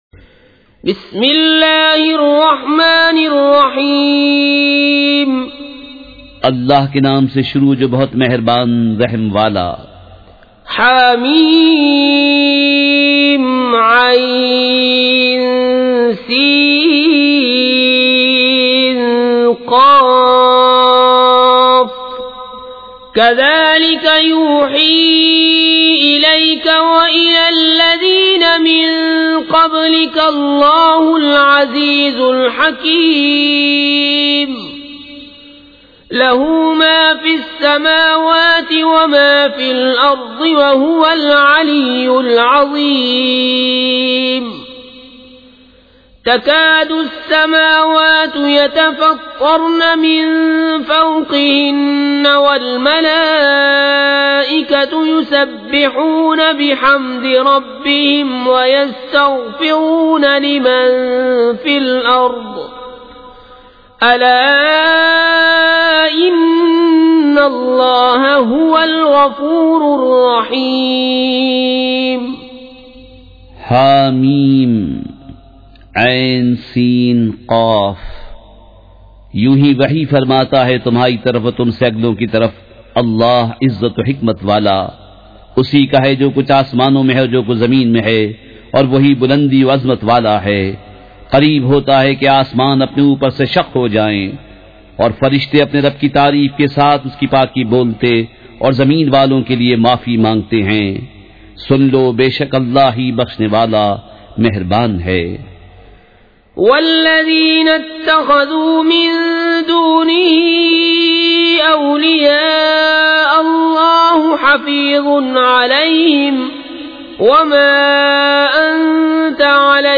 سورۃ الشوریٰ مع ترجمہ کنزالایمان ZiaeTaiba Audio میڈیا کی معلومات نام سورۃ الشوریٰ مع ترجمہ کنزالایمان موضوع تلاوت آواز دیگر زبان عربی کل نتائج 1723 قسم آڈیو ڈاؤن لوڈ MP 3 ڈاؤن لوڈ MP 4 متعلقہ تجویزوآراء